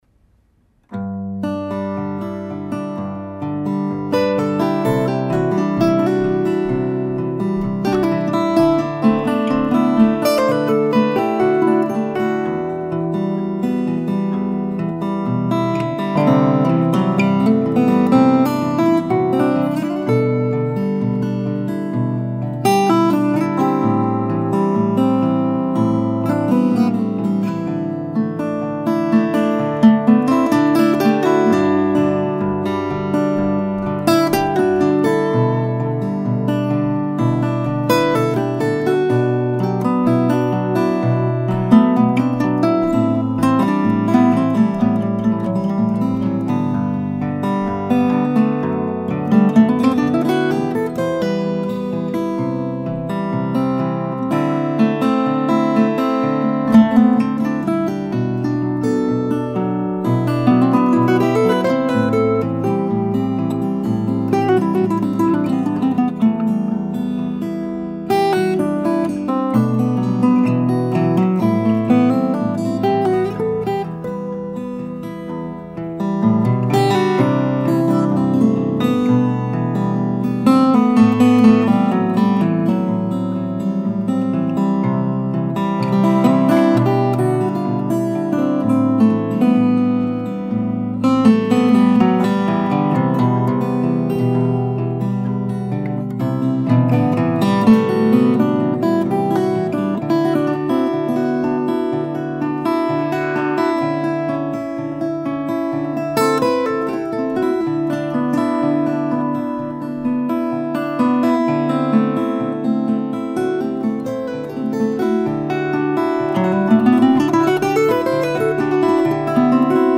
Just a simple two part improvisation in free form.
Not a big sound but kind of light and sweet maybe.
This is just so relaxing.
Yep, same mic…. Schoeps mk41 about 2 ft out from the neck/body joint.
There's an excelent sound on your acoustic.
I like the spatial...sounds like the rythm is a bit left and the lead a bit right...almost how you might see this being played live.
A few strange transitional notes, but that's the great thing about improv.